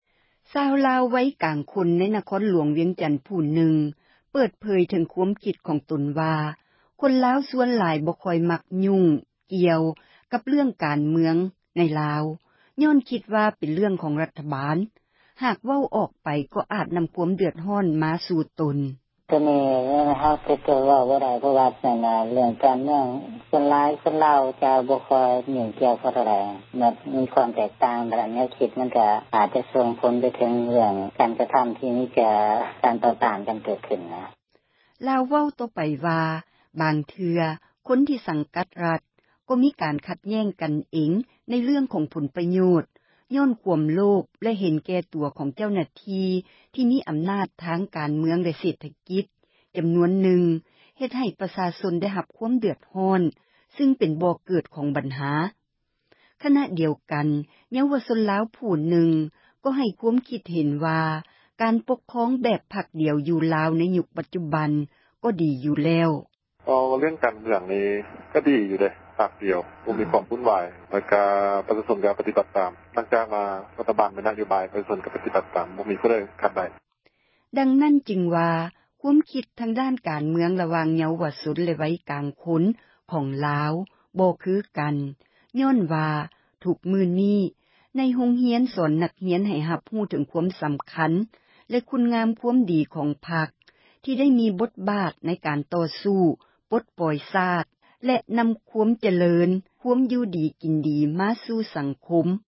ຊາວລາວ ວັຍກາງຄົນ ໃນນະຄອນຫລວງ ວຽງຈັນ ຜູ້ນຶ່ງ ເປີດເຜີຍ ເຖິງຄວາມຄິດ ຂອງຕົນວ່າ ຄົນລາວ ສ່ວນຫລາຍ ບໍ່ຄ່ອຍມັກ ຫຍຸ້ງກ່ຽວກັບ ເຣື່ອງ ການເມືອງ ຍ້ອນຄິດວ່າ ເປັນເຣື່ອງ ຂອງຣັຖບານ ຫາກເວົ້າ ອອກໄປ ກໍອາດ ນໍາຄວາມ ເດືອດຮ້ອນ ມາສູ່ຕົນ:
ໃນຂະນະ ດຽວກັນ ເຍົາວະຊົນ ລາວ ຜູ້ນຶ່ງ ກໍໃຫ້ ຄວາມຄິດເຫັນວ່າ ການປົກຄອງ ແບບ ພັກດຽວ ຢູ່ລາວ ໃນຍຸກ ປັດຈຸບັນ ກໍດີຢູ່ແລ້ວ: